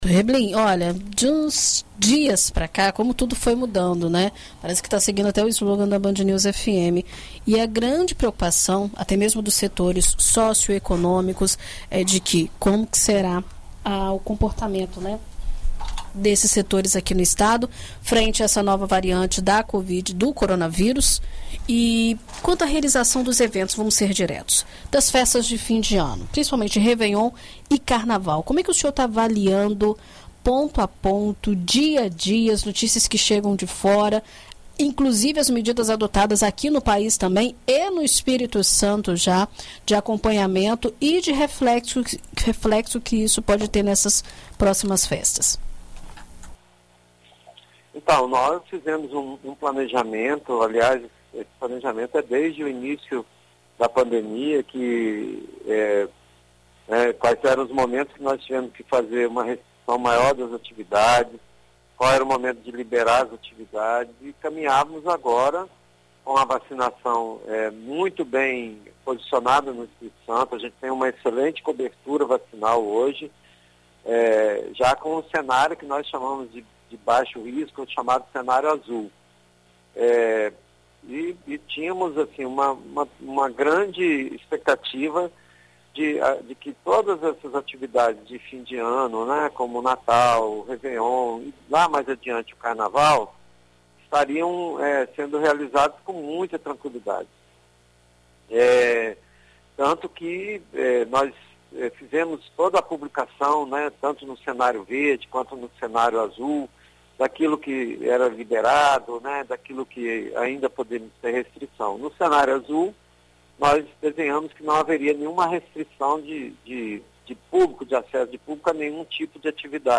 O Espírito Santo deve receber neste domingo (5) um novo lote de vacinas da Janssen para iniciar a aplicação da segunda dose do imunizante entre as pessoas que receberam a dose única do medicamento. A confirmação foi feita na manhã desta terça-feira (30) pelo subsecretário de Vigilância em Saúde, Luiz Carlos Reblin, em entrevista à rádio BandNews FM.